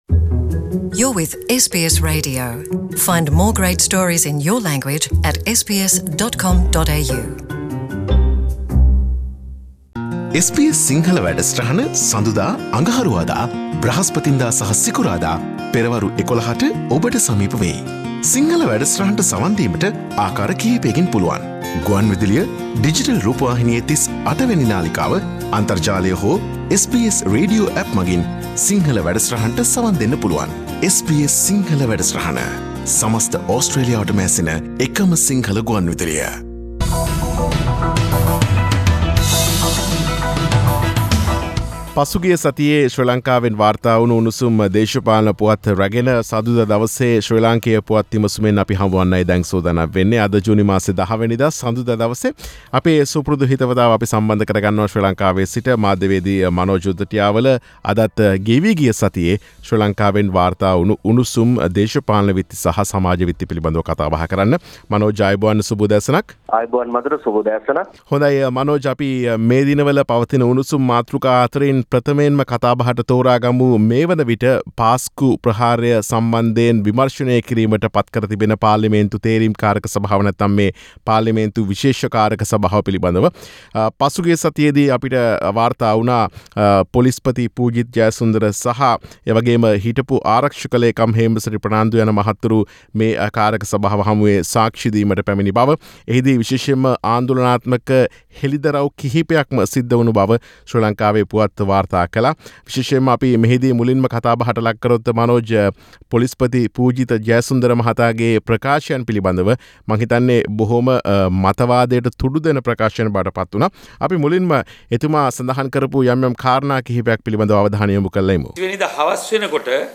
සතියේ දේශපාලන පුවත් සමාලෝචනය